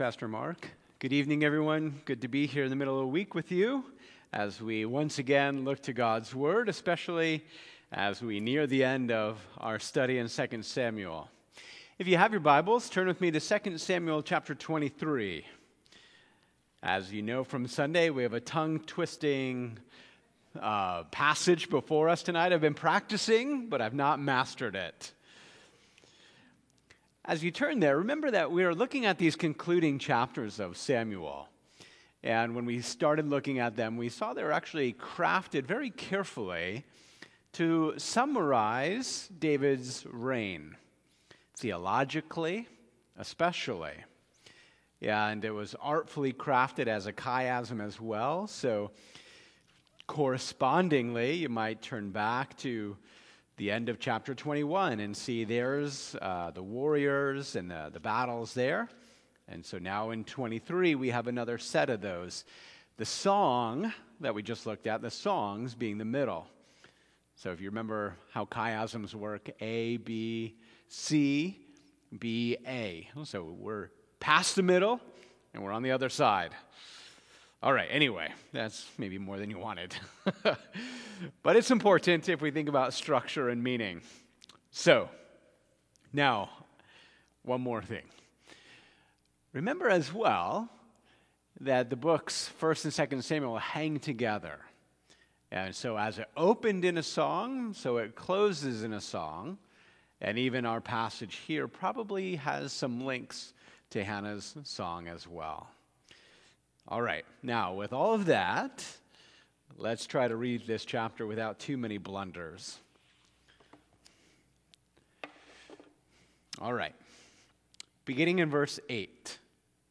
Occasion: Wednesday Evening